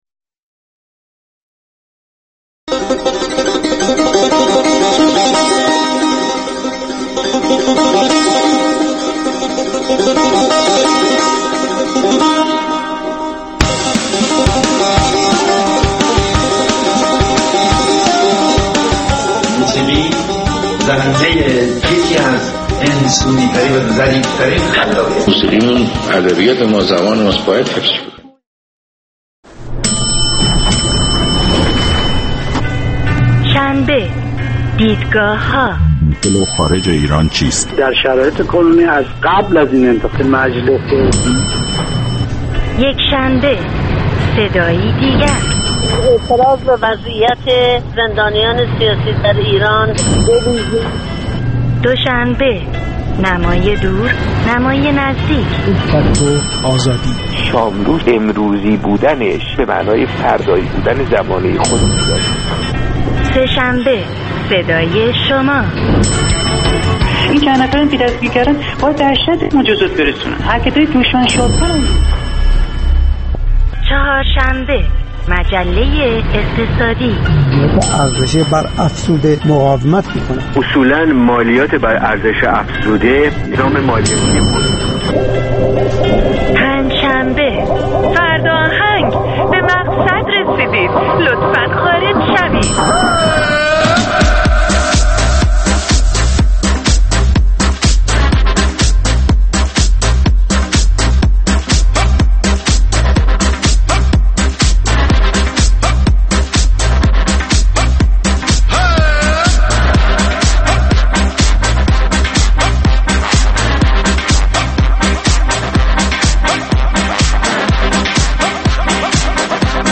پنجشنبه‌ها از ساعت هشت شب به مدت دو ساعت با برنامه زنده موسیقی رادیو فردا همرا ه باشید.